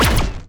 poly_shoot_laser02.wav